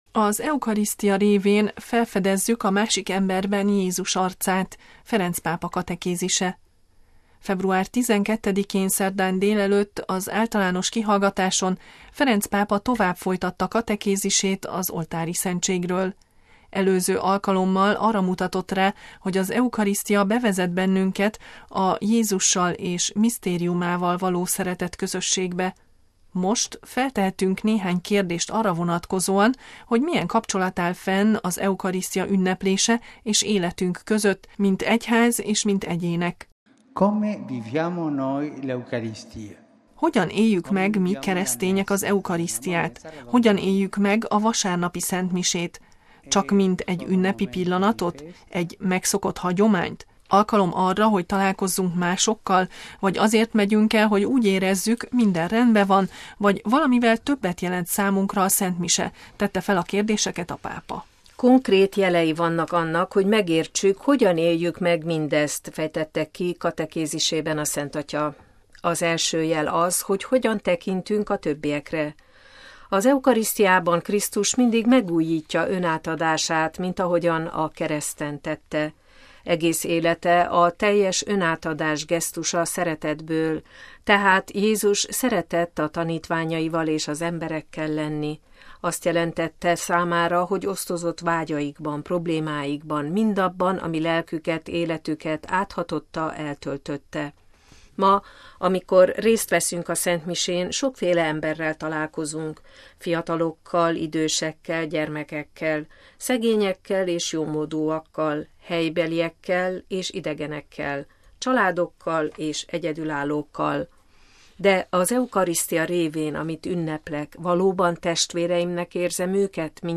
MP3 Február 12-én, szerdán délelőtt, az általános kihallgatáson Ferenc pápa folytatta katekézisét az Oltáriszentségről.